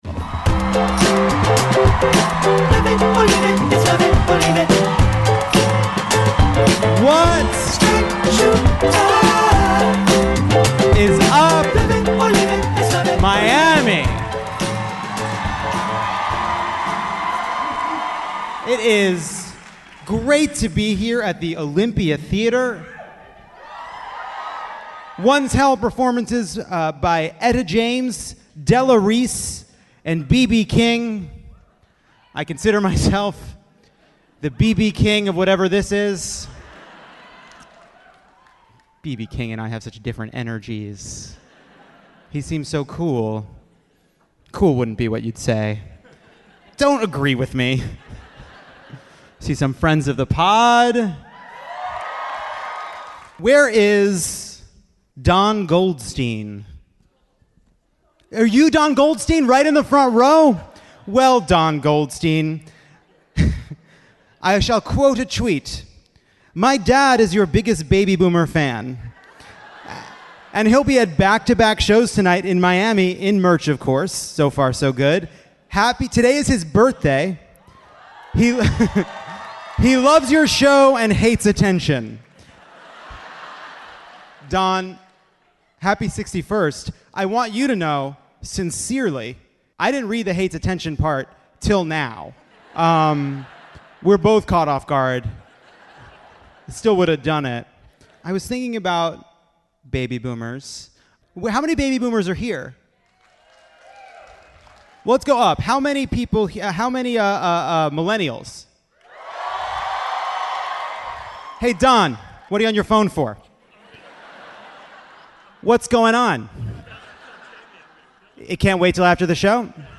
Live from Miami